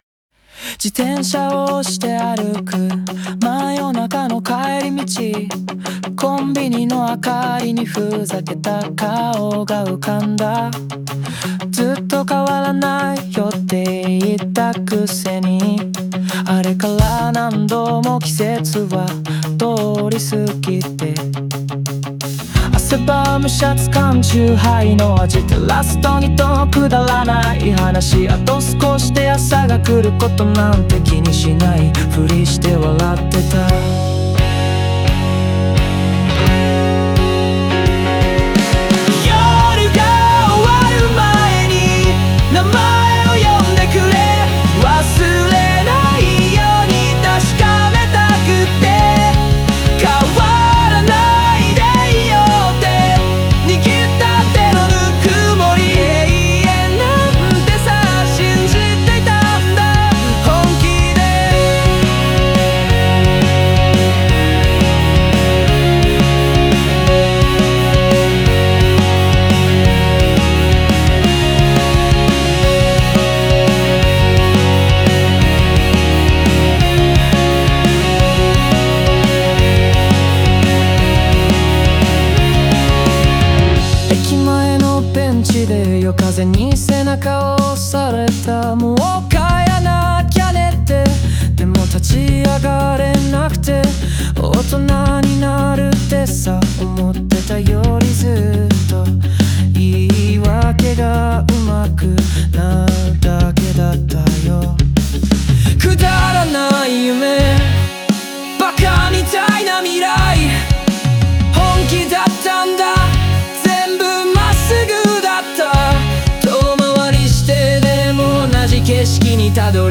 この歌は、青春の終わりと友情の記憶を描いたエモーショナルなロックナンバー。